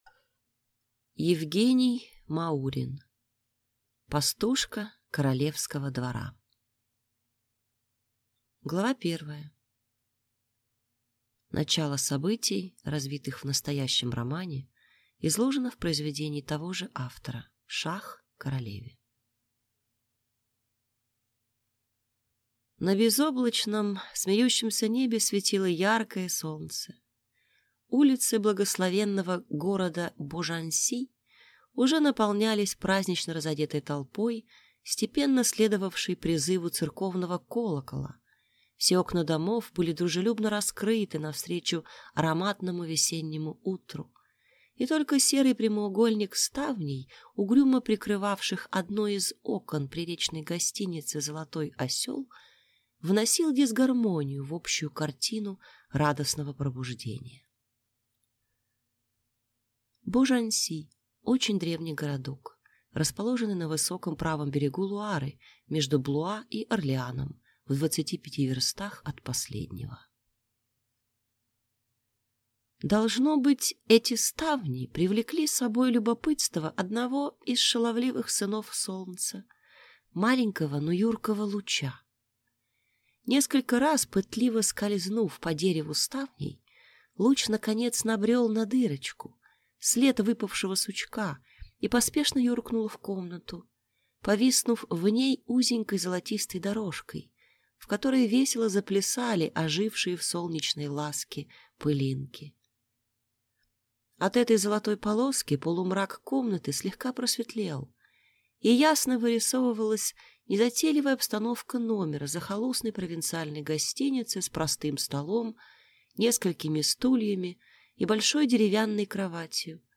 Аудиокнига Пастушка королевского двора | Библиотека аудиокниг